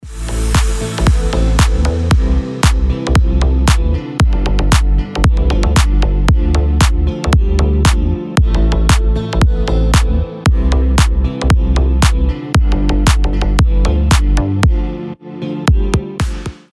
гитара
красивые
deep house
без слов
ремиксы